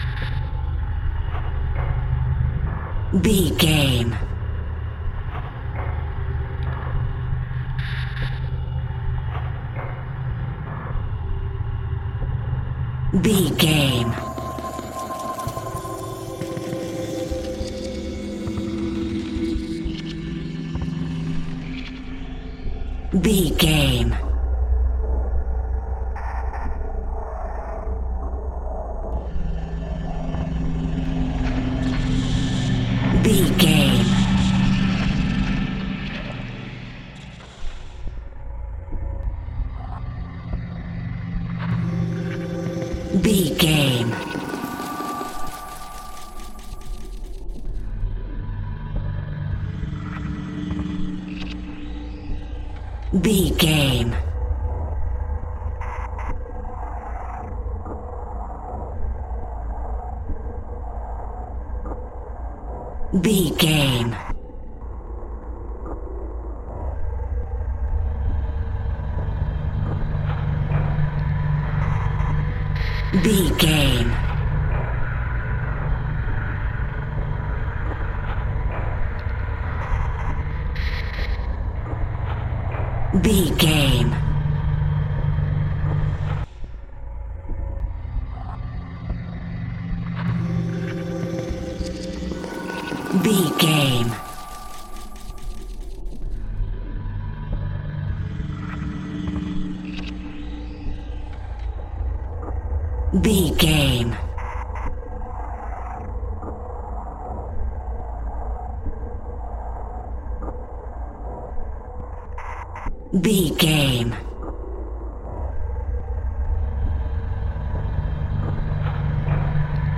Aeolian/Minor
tension
ominous
dark
eerie
Horror Pads
horror piano
Horror Synths